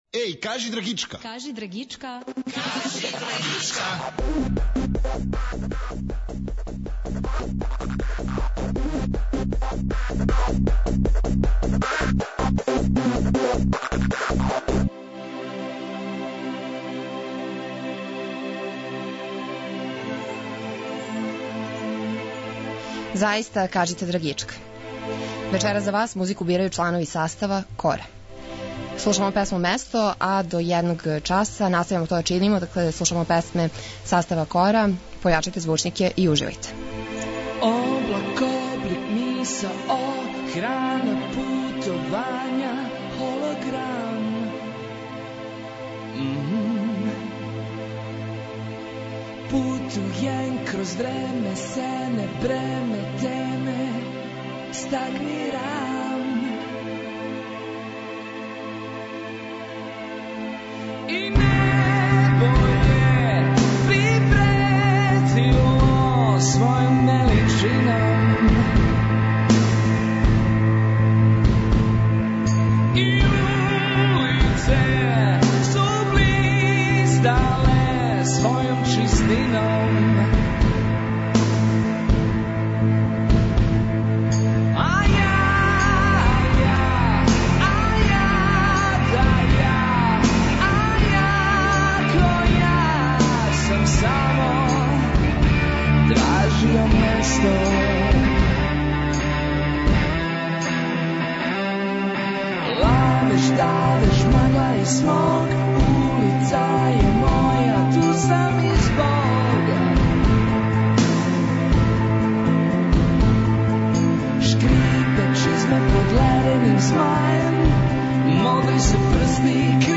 Чланови састава КОРА су гости ноћашње Драгичке.
Поред песама са новог албума, слушамо и оне других извођача, које они предлажу.